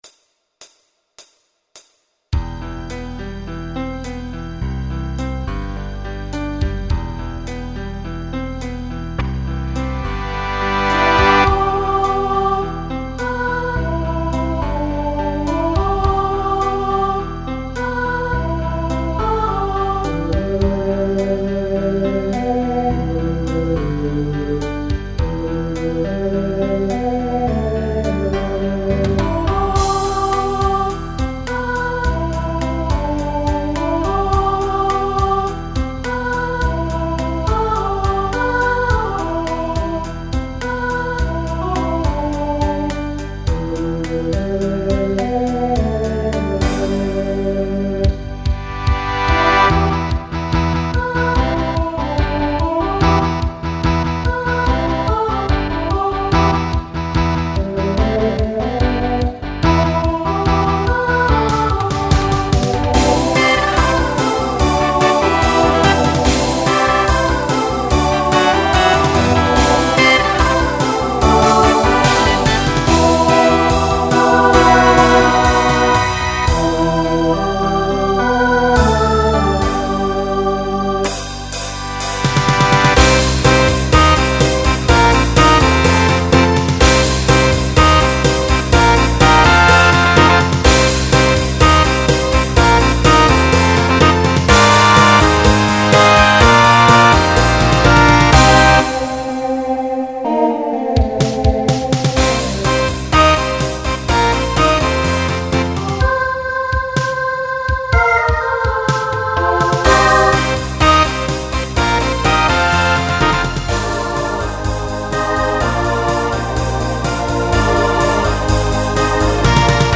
Starts with a quintet to facilitate a costume change.
Genre Pop/Dance
Transition Voicing SATB